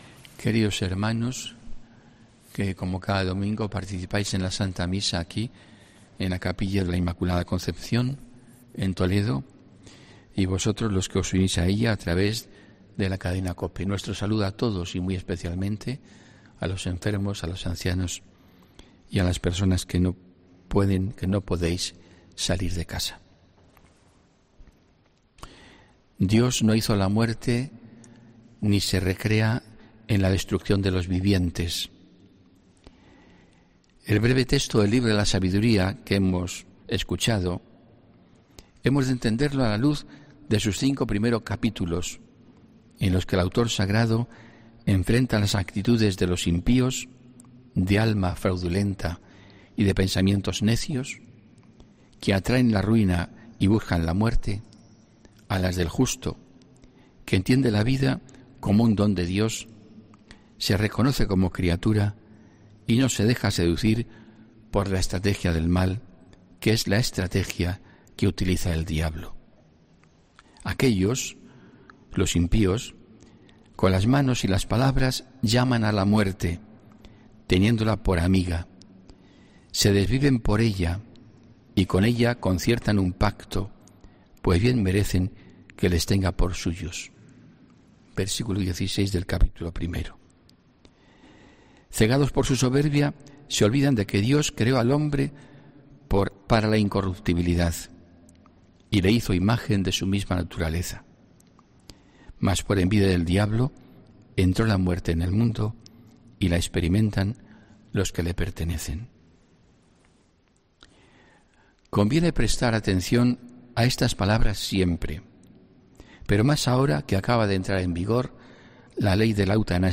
HOMILÍA 27 JUNIO 2021